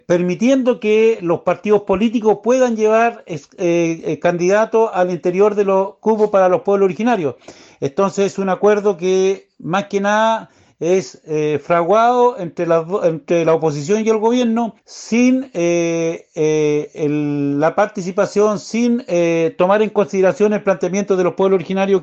Además, exigen que quienes ocupen los escaños no tengan militancia partidaria, tal como definió la ciudadanía en el plebiscito del domingo. Así lo señaló el alcalde de Tirúa, Adolfo Millabur.